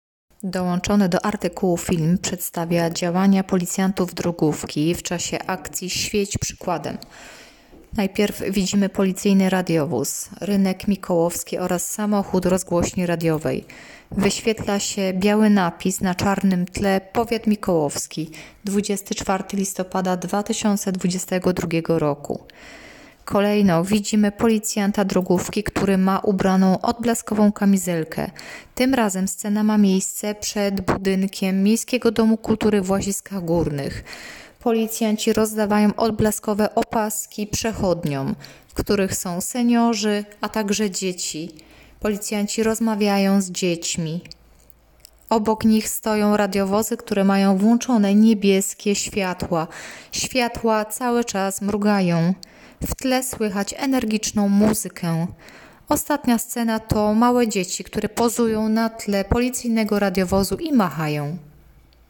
Nagranie audio Audiodeskrypcja do filmu